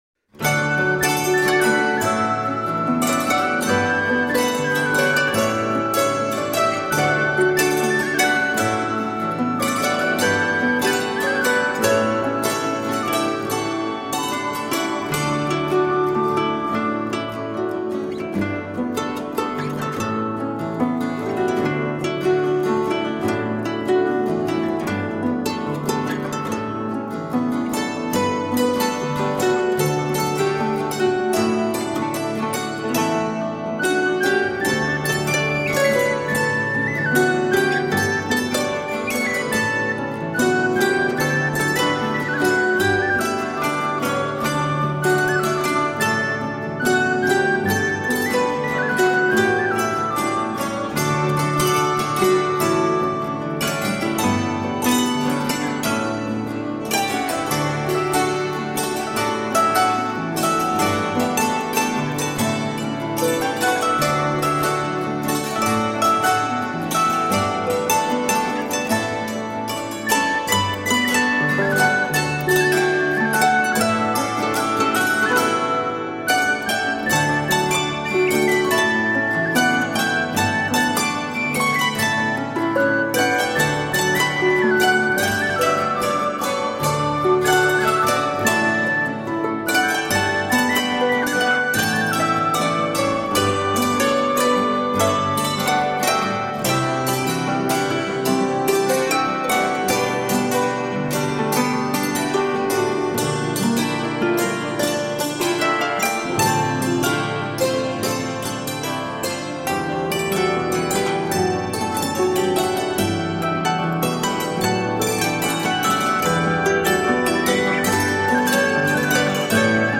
Timeless and enchanting folk music for the soul.
Tagged as: World, Folk, Christmas, Harp